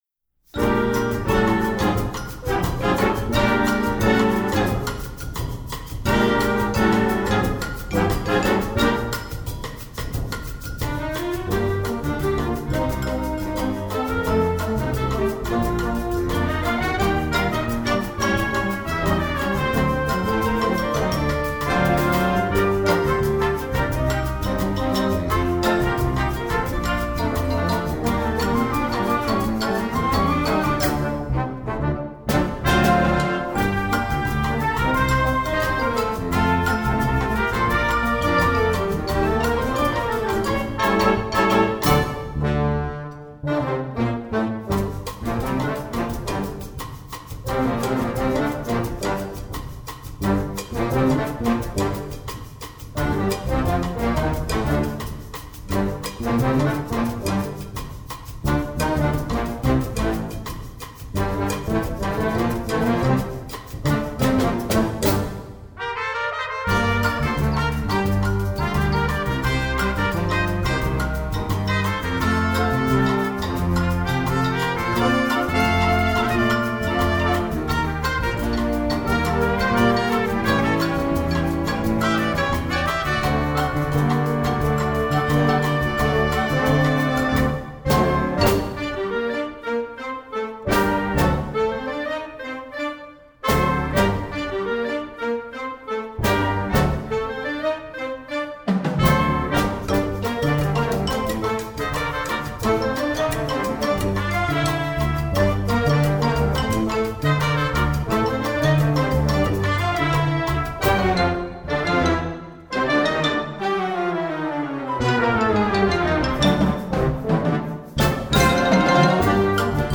Instrumentation: concert band
latin, multicultural